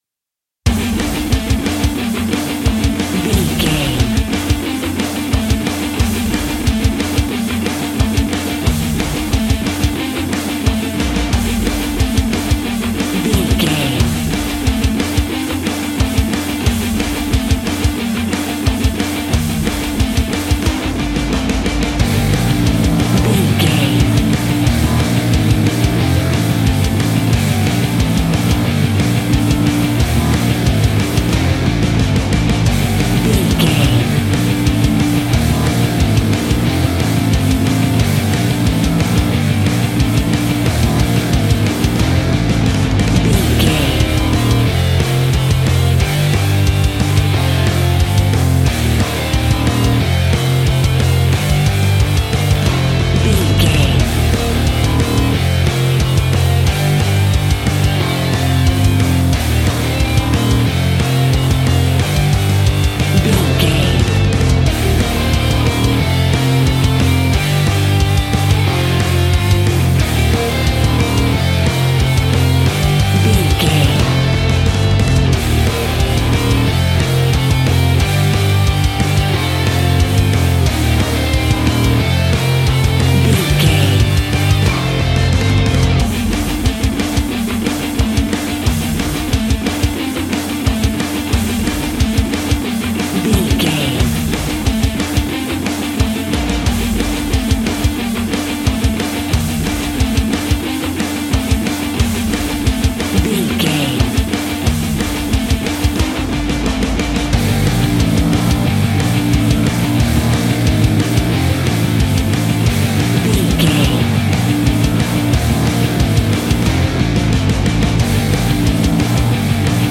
Epic / Action
Fast paced
Aeolian/Minor
hard rock
guitars
heavy metal
instrumentals
Heavy Metal Guitars
Metal Drums
Heavy Bass Guitars